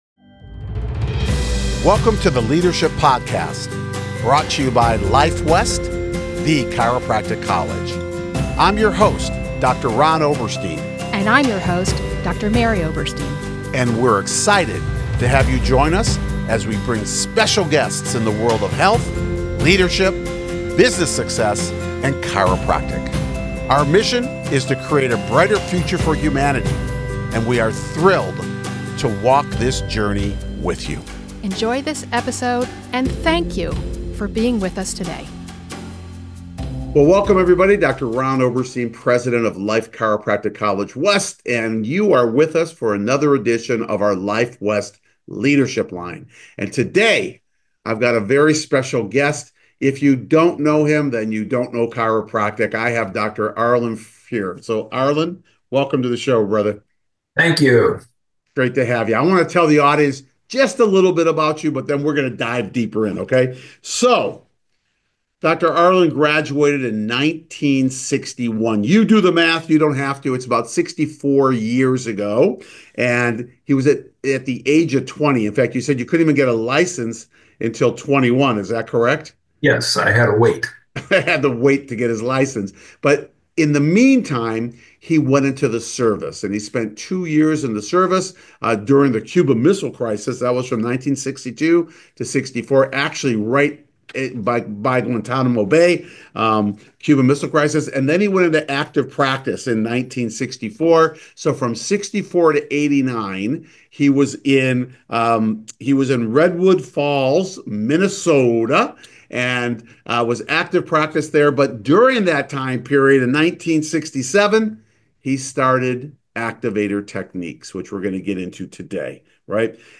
an inspiring conversation